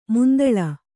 ♪ mundaḷa